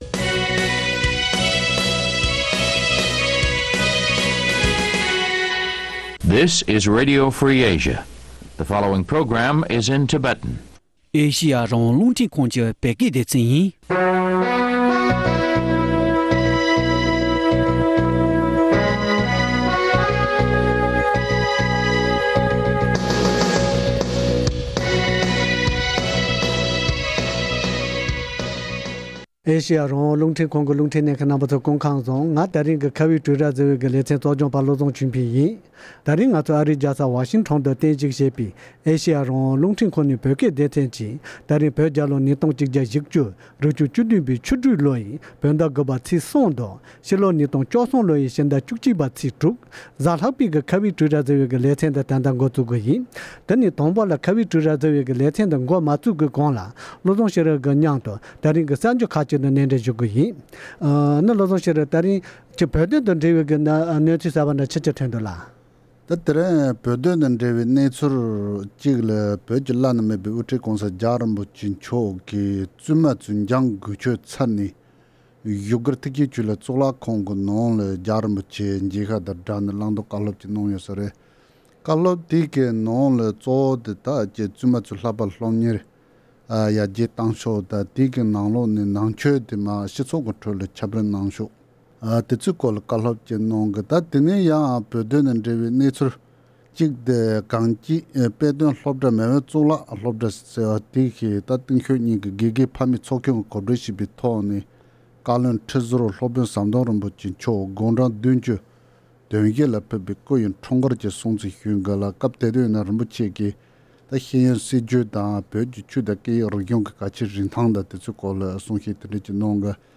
ལྷན་དུ་གླེང་མོལ་ཞུས་པ་ཞིག་གསན་རོགས་གནང་།།